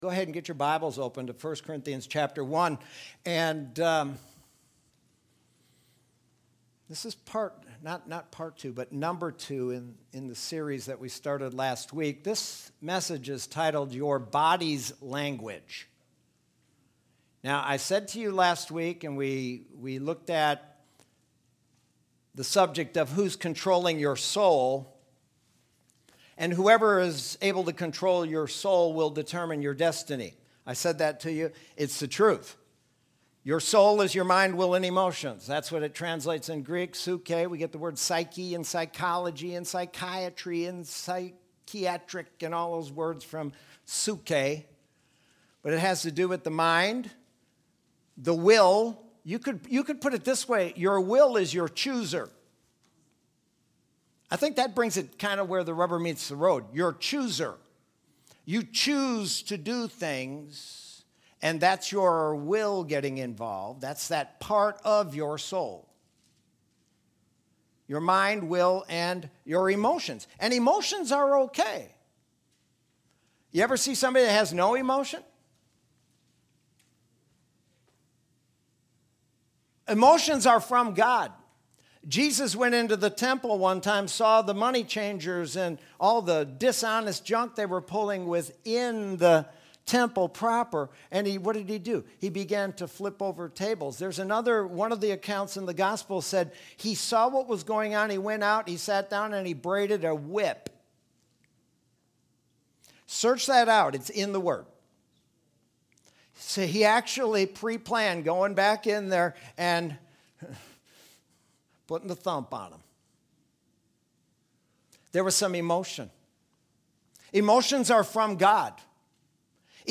Sermon from Sunday, August 2nd, 2020.